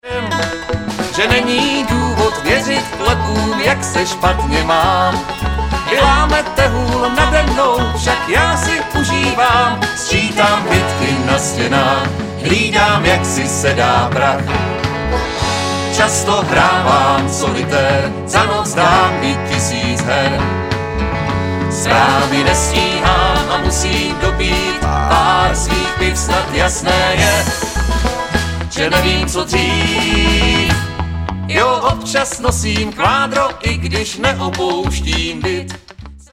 Sólový zpěv